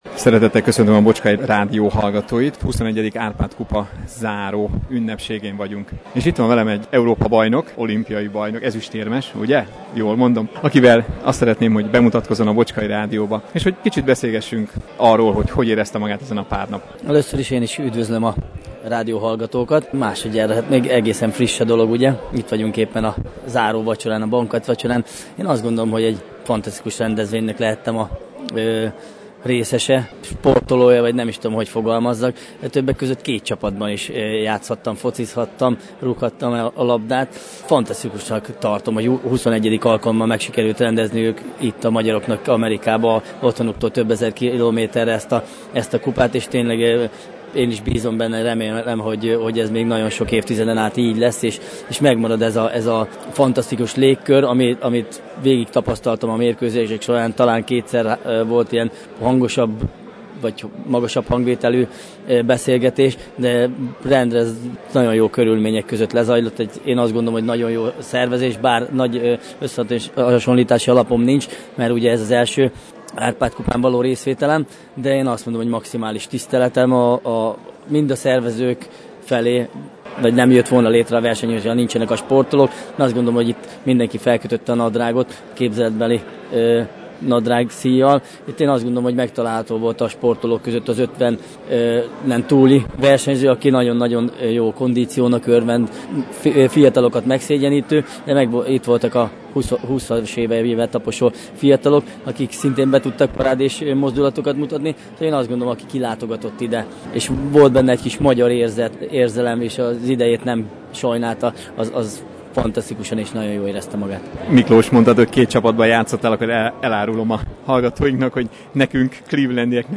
Ezután Ungvári Miklóst kértem meg, meséljen egy picit magáról és arról, hogy hogyan készül egyedüli sportolóként egy lehetséges 6. Olimpiai szereplésre. (Az interjú itt is meghallgatható a vasárnapi rádióadásunk után).